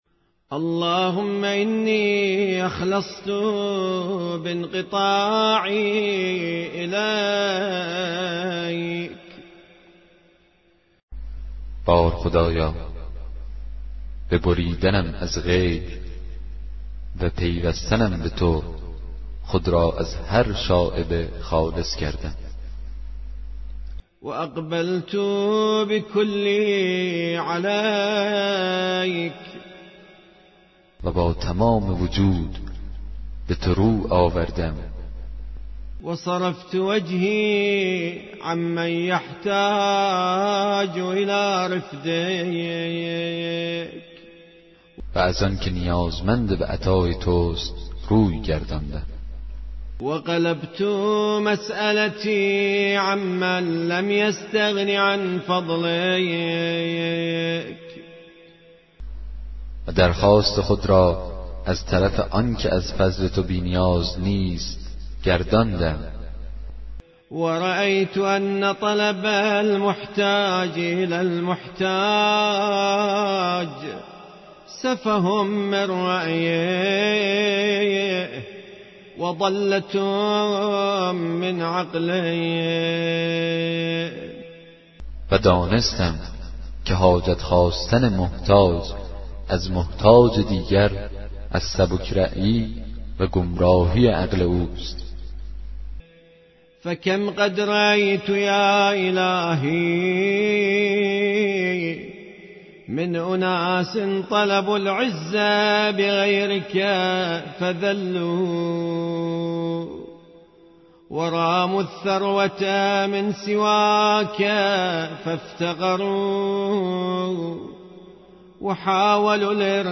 کتاب صوتی دعای 28 صحیفه سجادیه